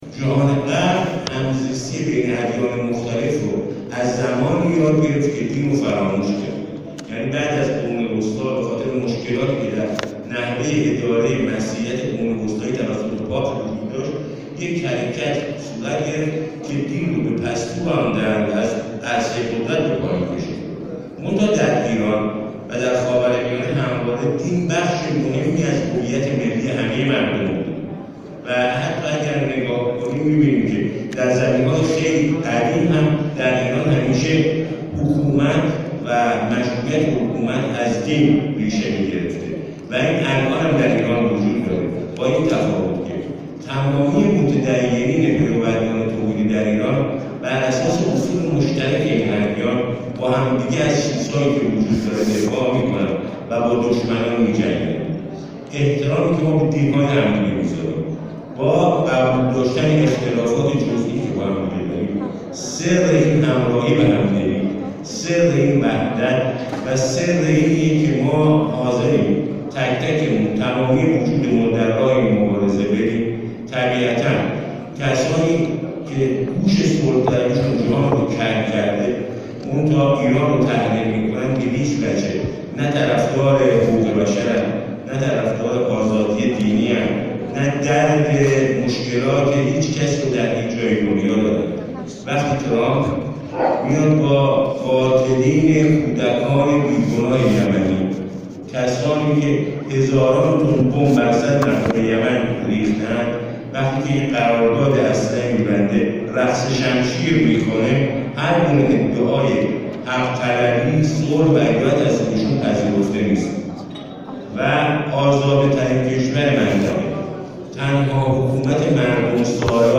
به گزارش خبرنگار خبرگزاری رسا، سیامک مره صدق نماینده کلیمیان در مجلس شورای اسلامی، امروز در همایش تحریم در تقابل با صلح جهانی از نگاه ادیان که در سازمان فرهنگ و ارتباطات اسلامی برگزار شد، گفت: صلح در سایه تعامل محقق می شود.